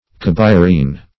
Cabirean \Cab`i*re"an\ (k[a^]b`[i^]*r[=e]"an)